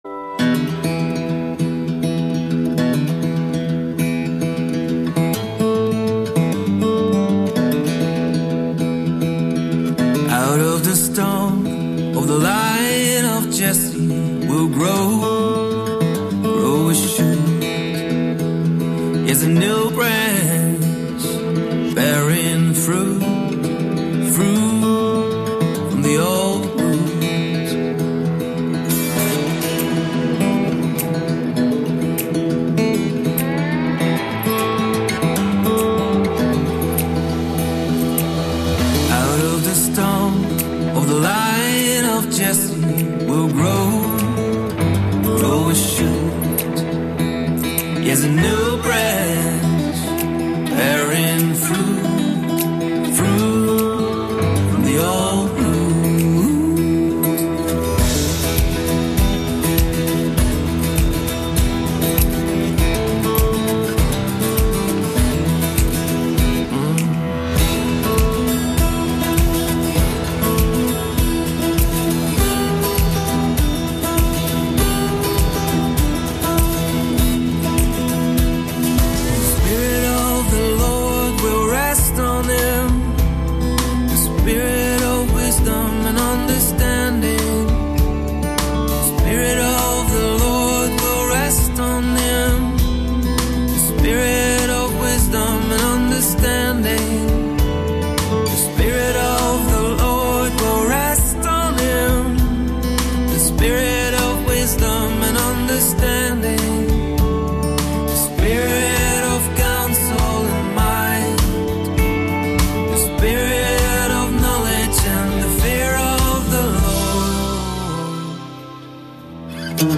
Music to lift, inspire,encourage and maybe raise some thought